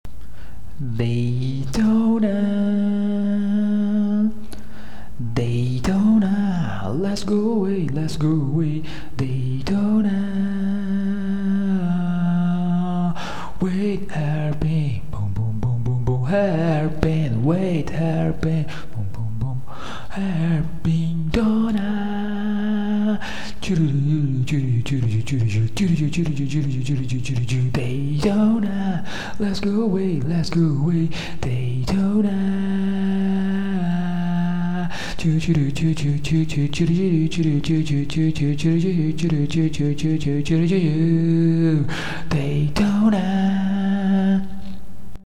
acapella whispering mix